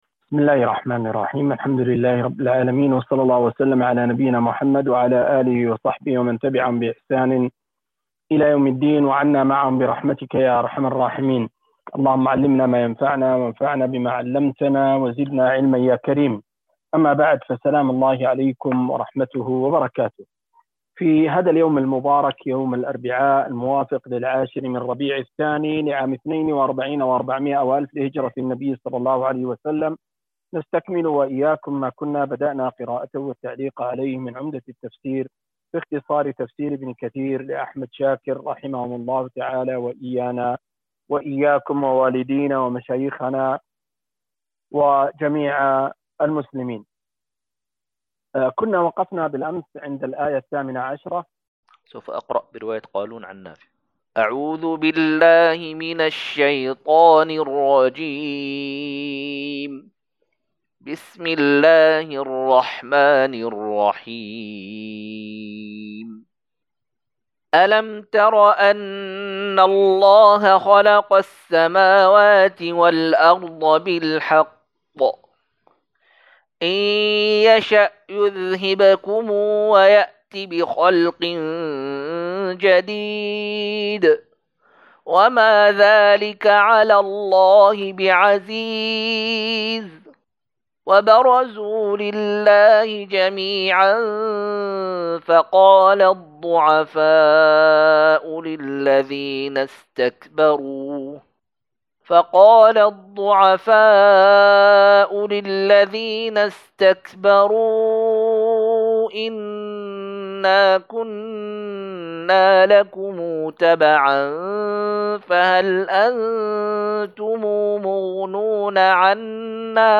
243- عمدة التفسير عن الحافظ ابن كثير رحمه الله للعلامة أحمد شاكر رحمه الله – قراءة وتعليق –